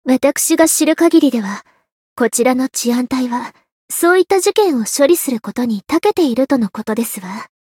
灵魂潮汐-安德莉亚-互动-不耐烦的反馈1.ogg